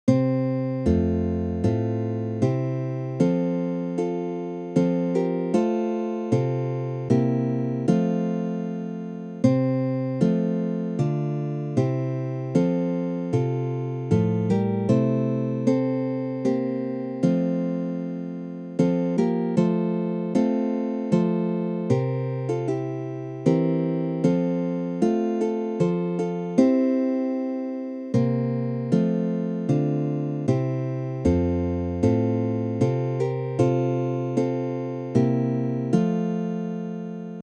für Bläser
Besetzung: Ensemble Kirchliches
Stimme in B (Flügelhorn/Trompete in B)